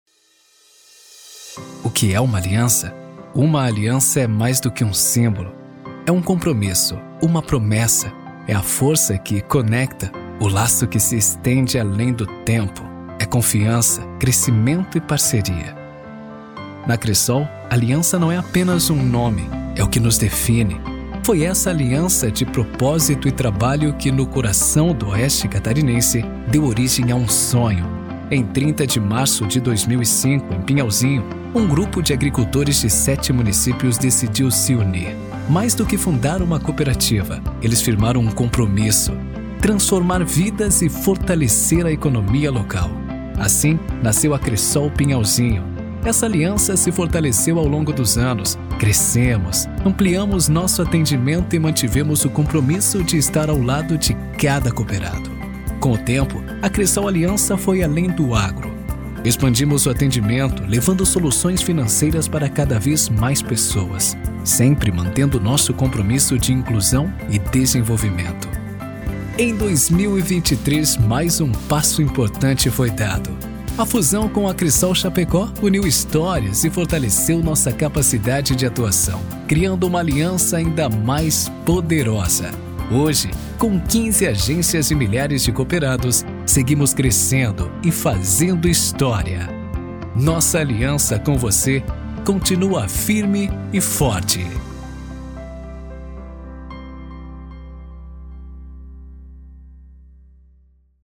VOZ VÍDEO CORPORATIVO: